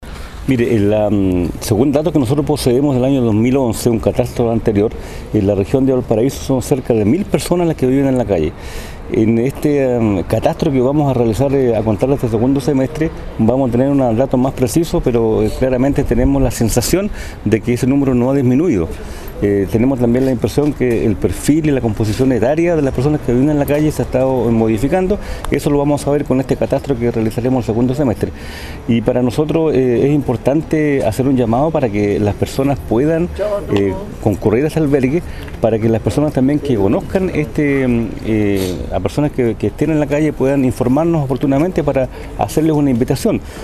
El Seremi indicó que prontamente se hará un catastro para verificar los datos etarios respecto al perfil de cada uno de ellos.